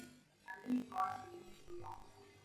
描述：噪声信号的去噪信号SNR为5dB
Tag: lab4 瀑布 维纳